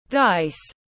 Xúc xắc tiếng anh gọi là dice, phiên âm tiếng anh đọc là /daɪs/